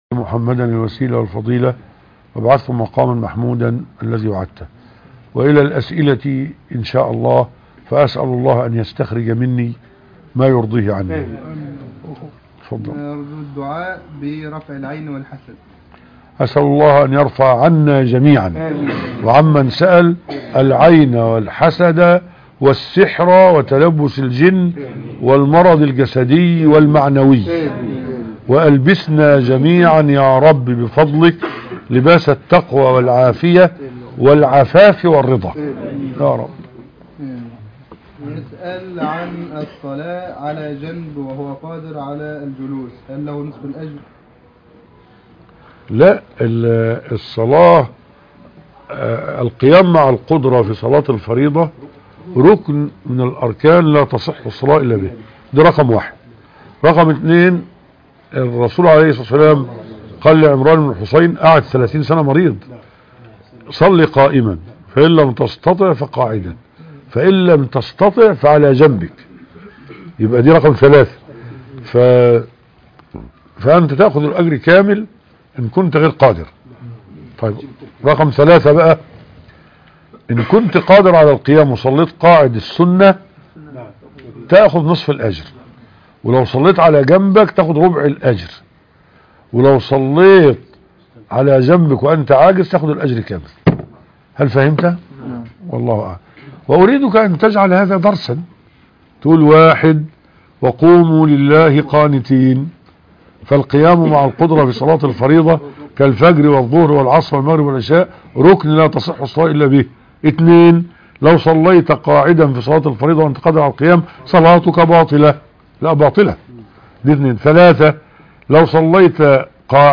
لقاء الفتاوى